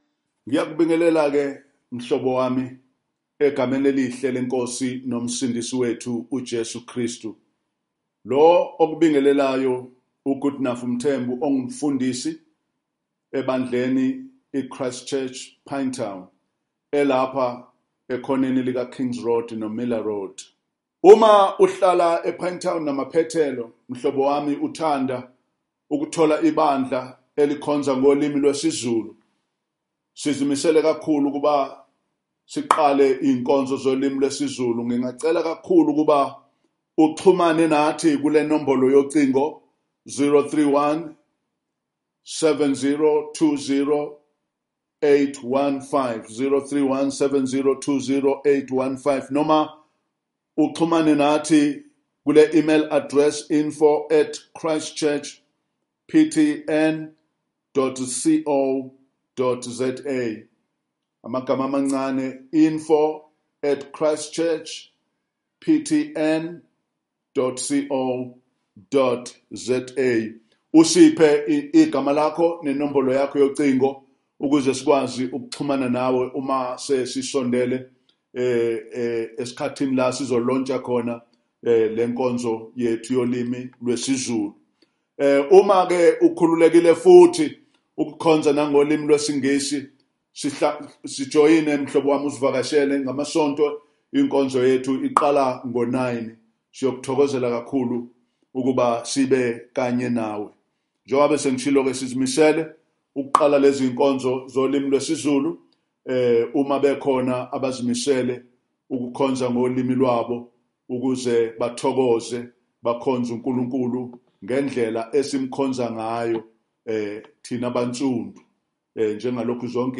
Passage: John 2:19-25 Event: Zulu Sermon